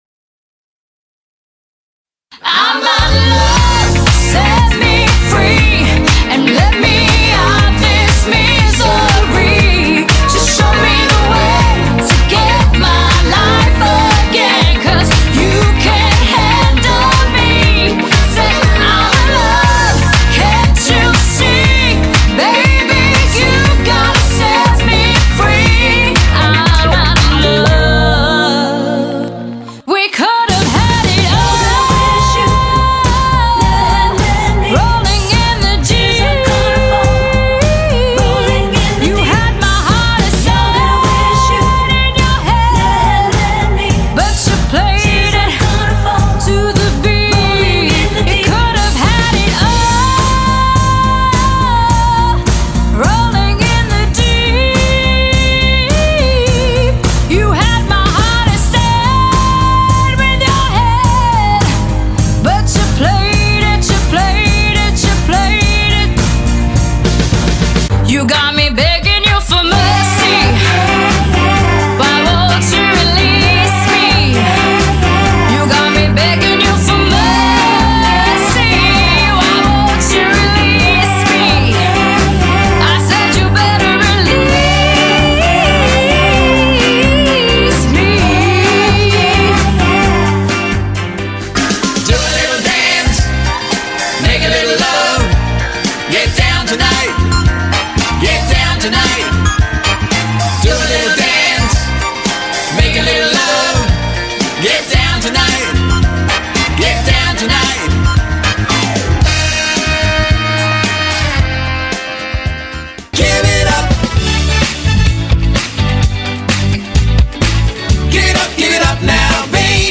”CLASSIC TUNES AND FUNKY DANCE GROOVES”
LIVE!!
Demo – Part 1